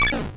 Retro Game Weapons Sound Effects – Sfx Wpn Laser1 – Free Music Download For Creators
Retro_Game_Weapons_Sound_Effects_-_sfx_wpn_laser1.mp3